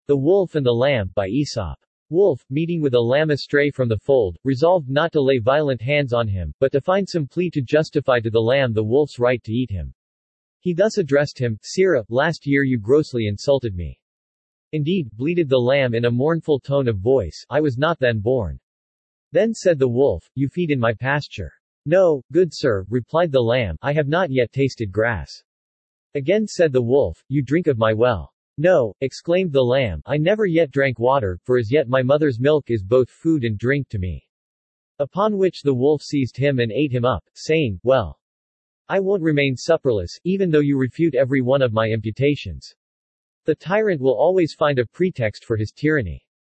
Standard (Male)